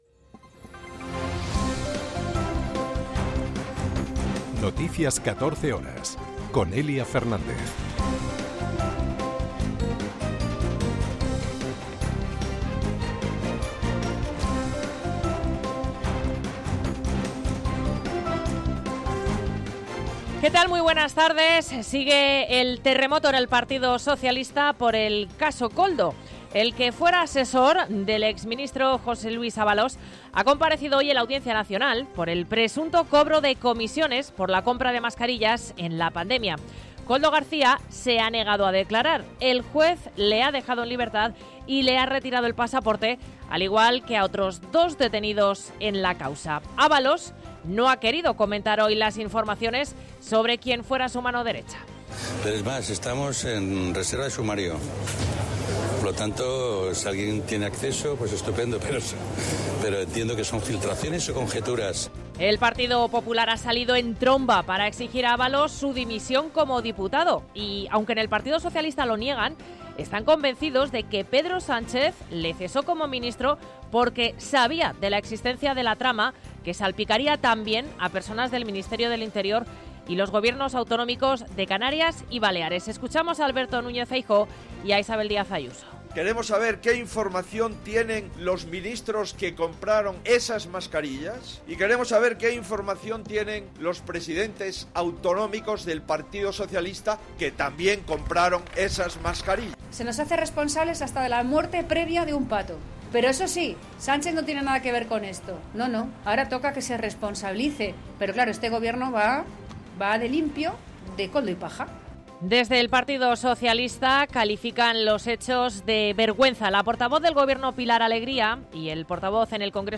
Noticias 14 horas 22.02.2024